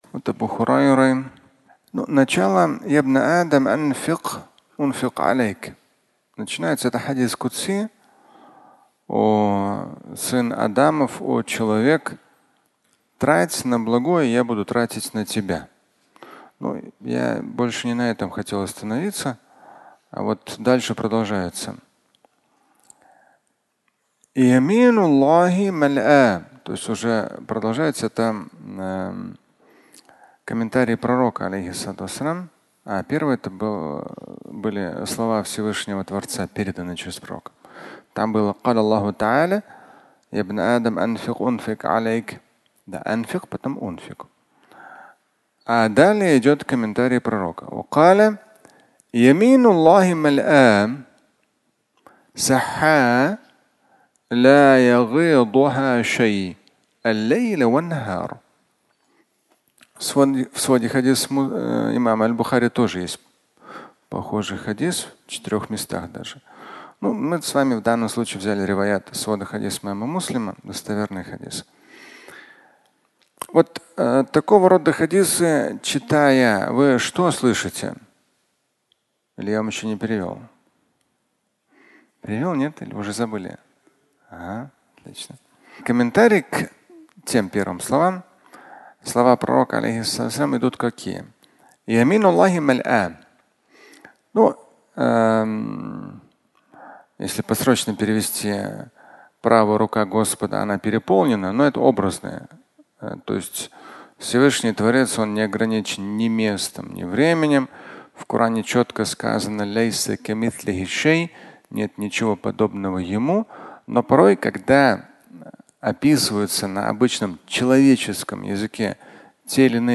Изобилие возможностей (аудиолекция)